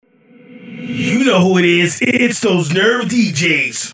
Acapellas